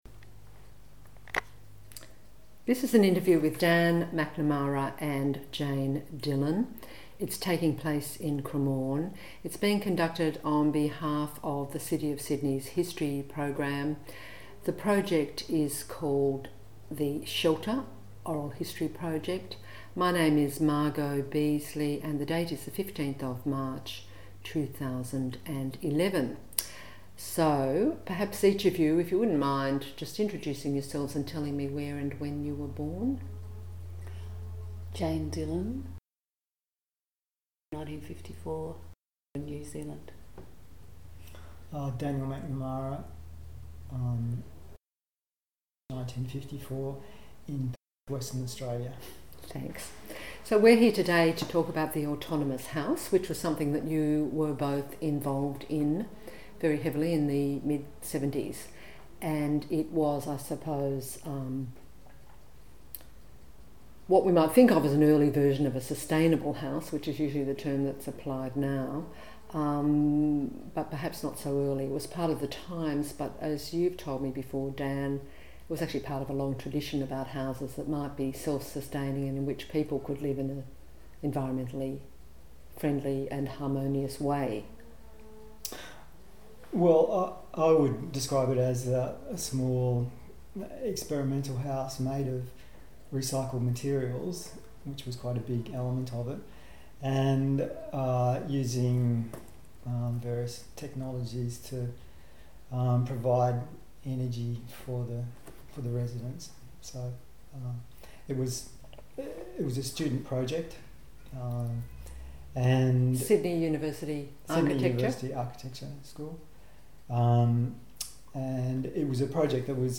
This interview is part of the City of Sydney's oral history theme: Shelter